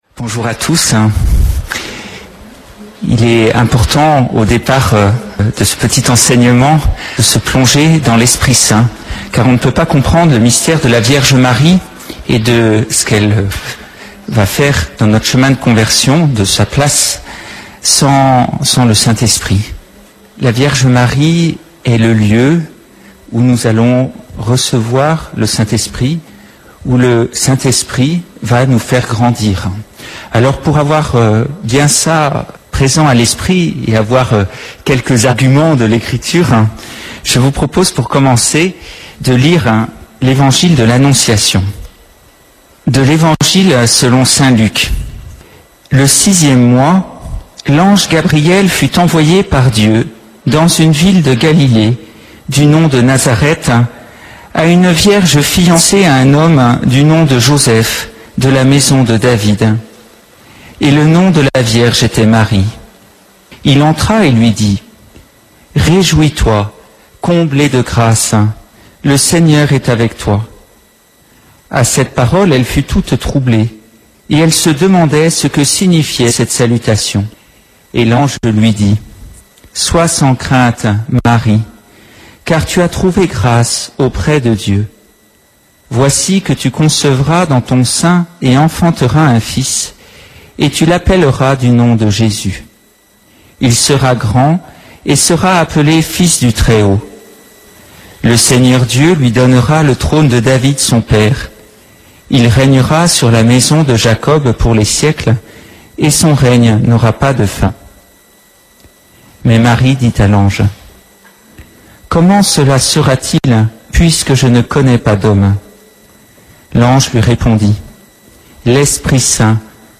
Un enseignement